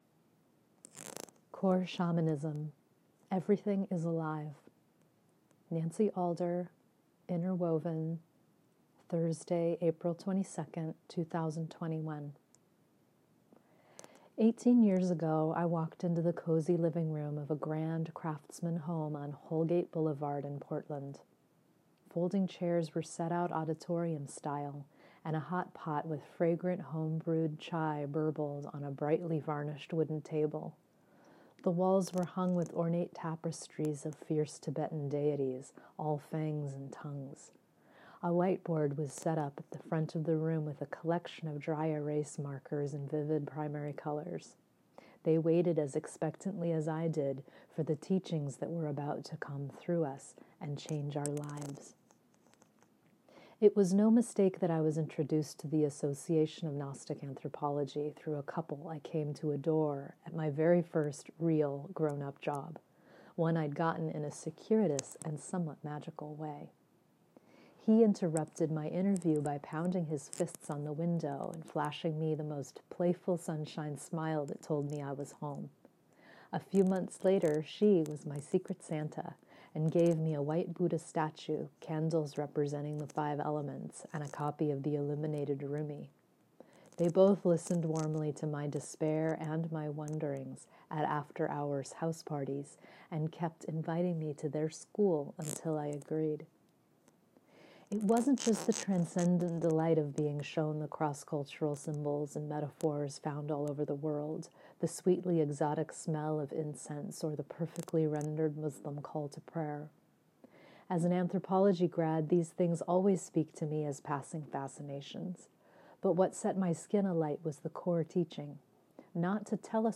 Enjoy this 12-minute read, or have me read it to you via the link on my website.